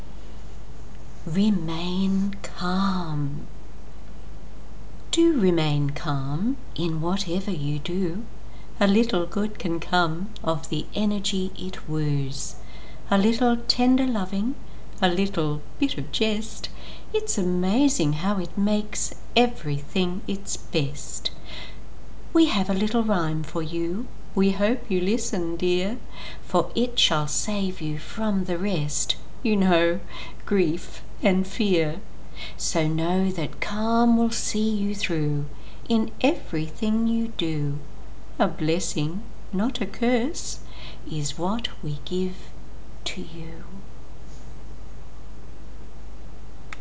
I love your accent! You have a great speaking voice!